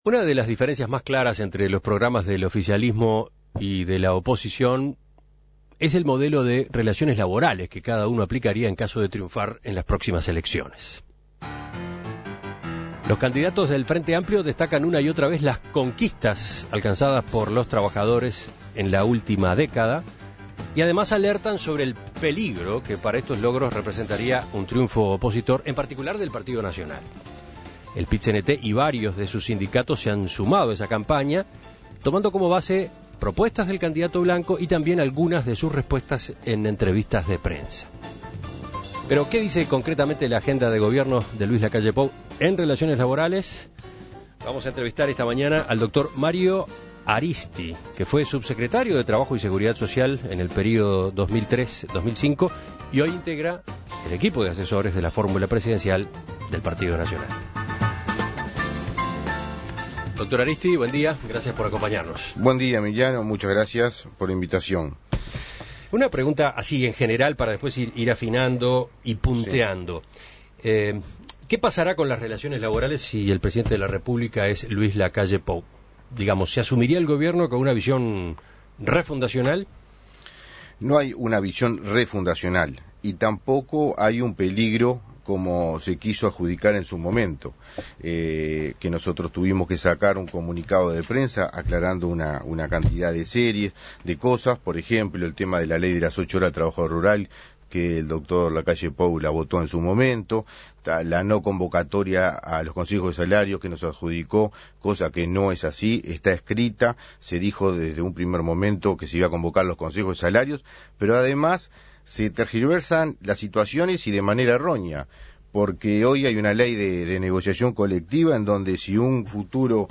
En Perspectiva entrevistó